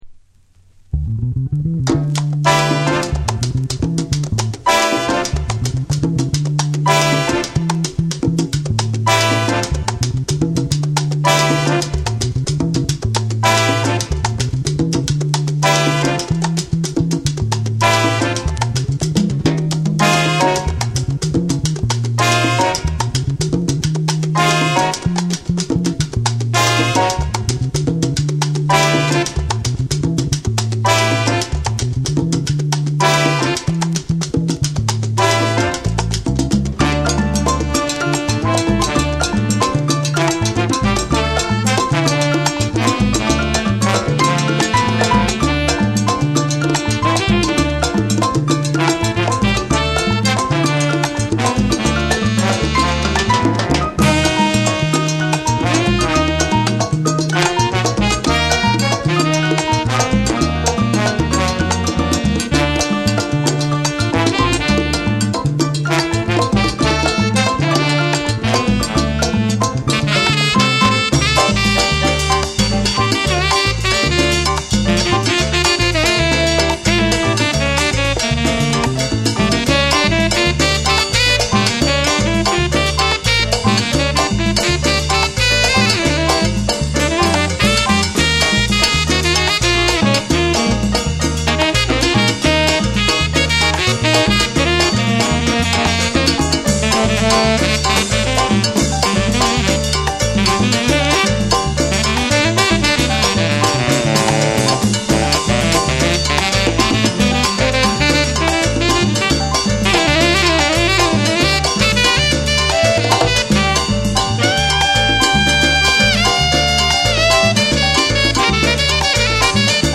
躍動感あふれるパーカッションのリズムが炸裂する
WORLD